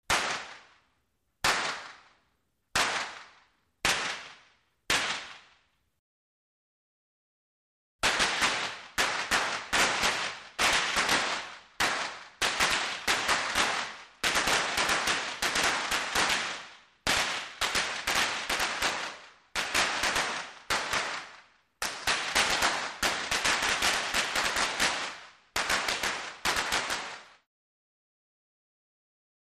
Firecracker Multiple Explosions, Medium Interior Perspective.